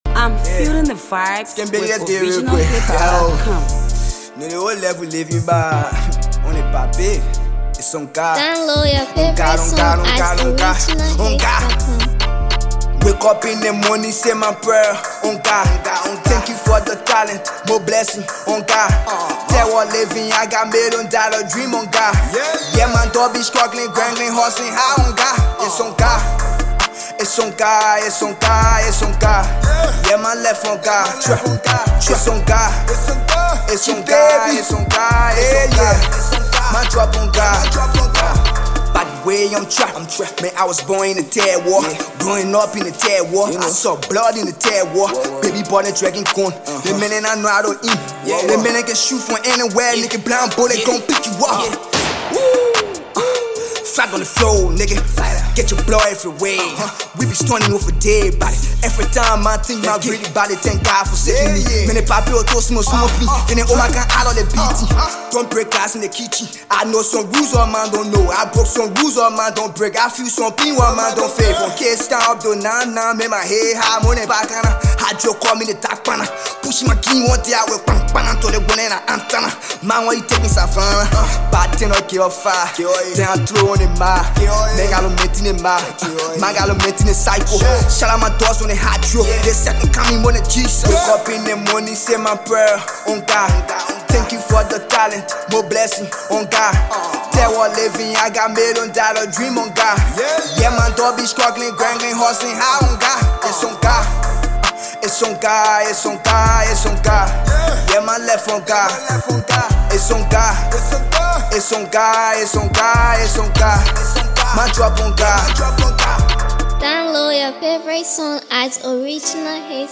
Liberian uprising trap artist